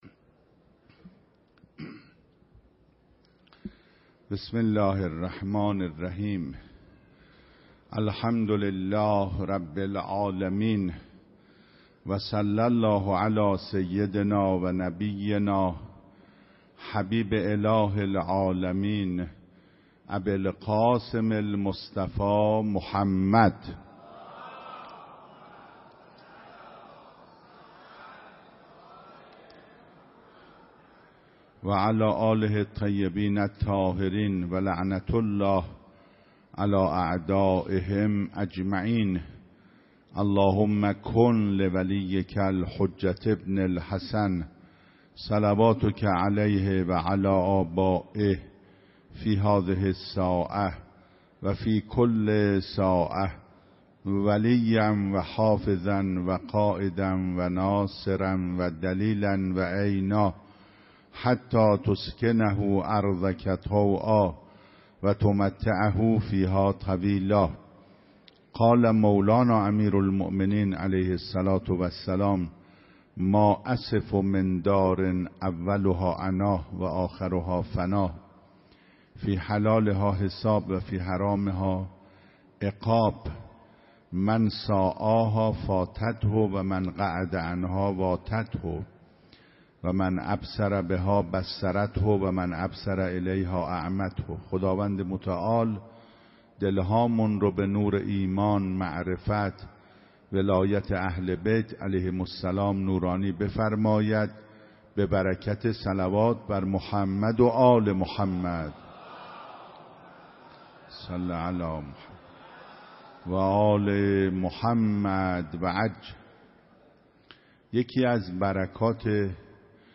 25 رمضان 96 - حرم حضرت معصومه - سخنرانی